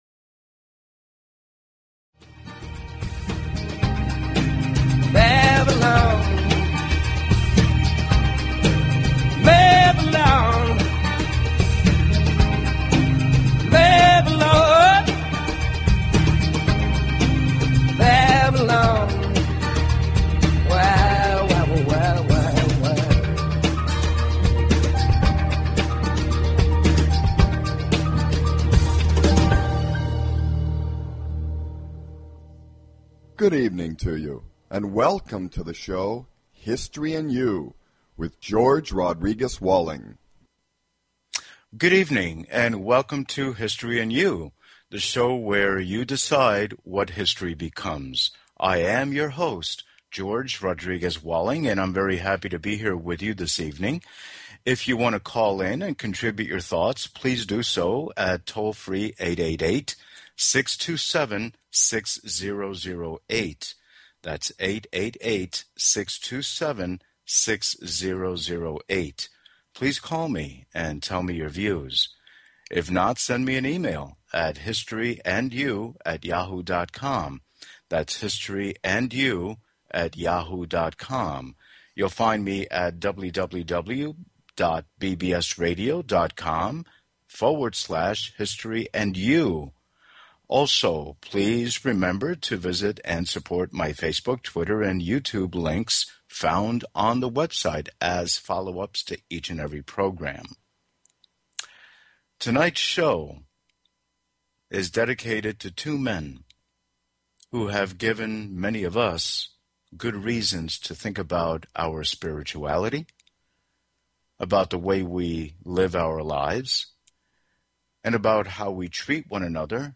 Therefore, the variety of topics and history surrounding everyday people will be made current and relevant through insightful discussions.